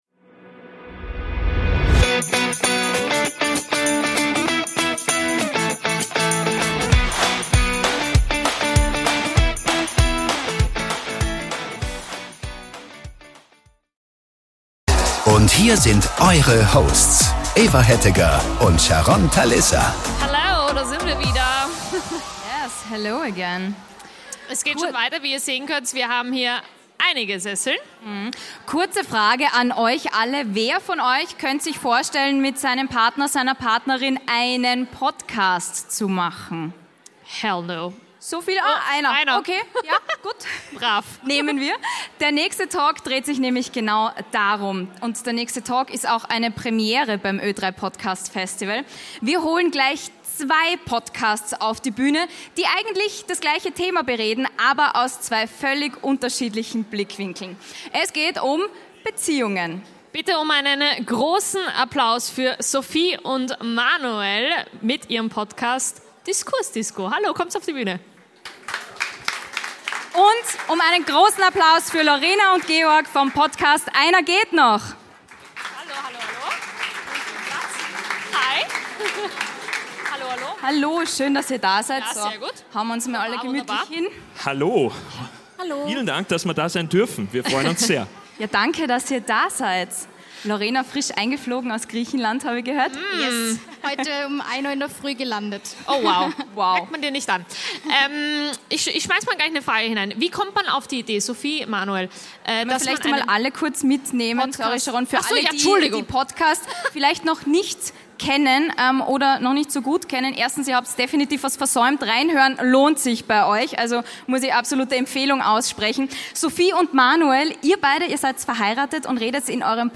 Hier unser Auftritt beim Ö3 Podcastfestival zum Thema "Beziehungen und Podcast" für euch nochmal zum Nachhören